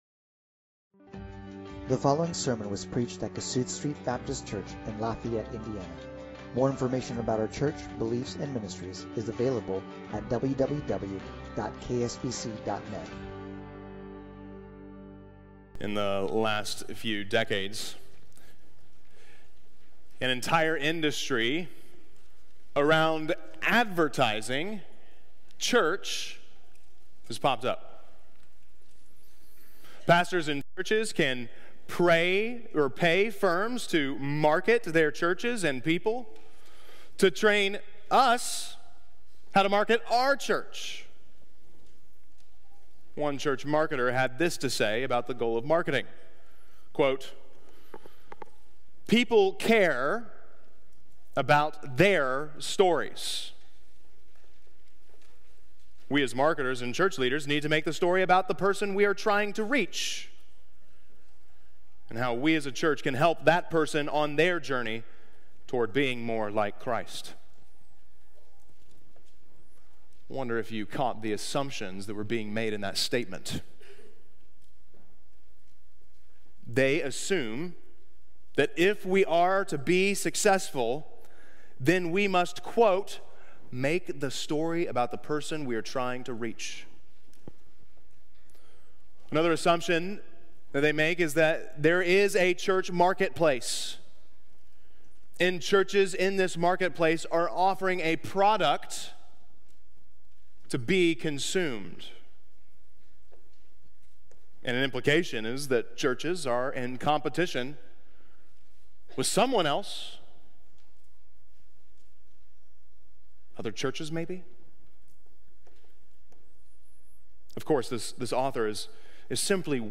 Kingdom Expansion | Kossuth Street Baptist Church